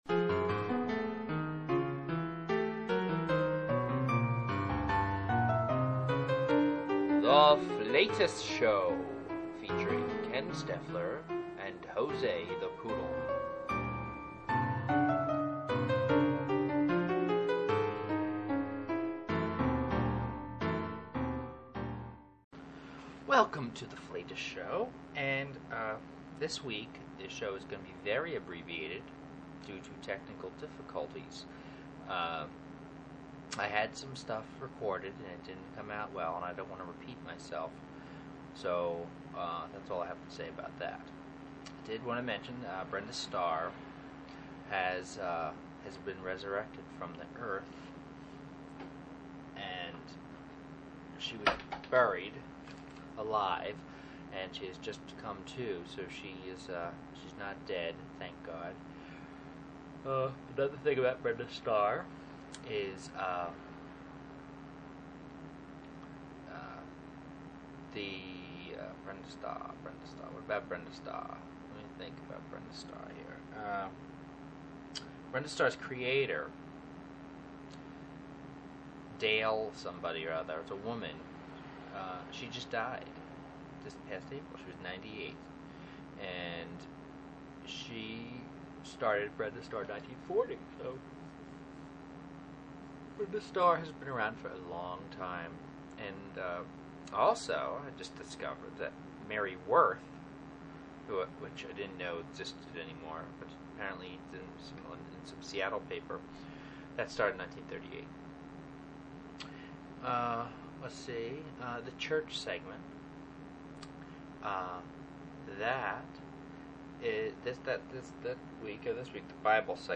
The Flatus Show is a weekly variety podcast in vaudeville style.
An abbreviated second show. Still working out the kinks and had a mjor technical meltdown today. The Flatus Show 2